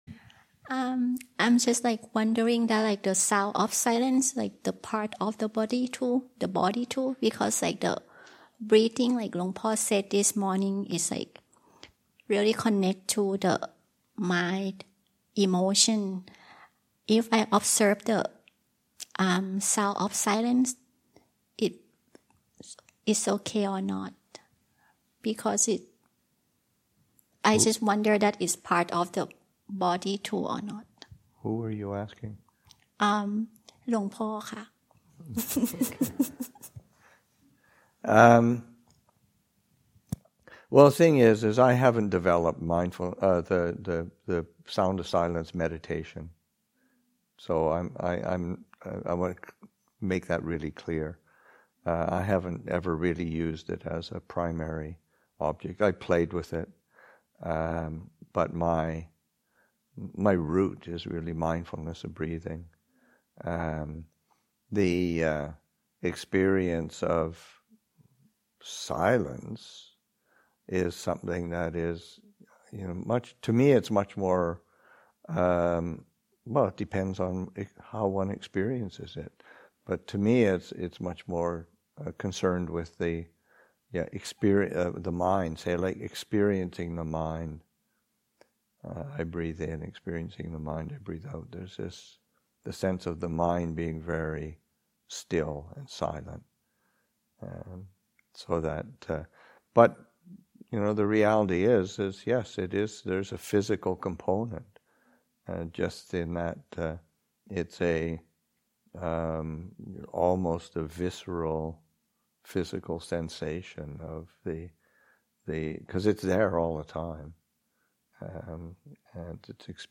Ānāpānasati Daylong at Abhayagiri, Session 8 – Sep. 9, 2023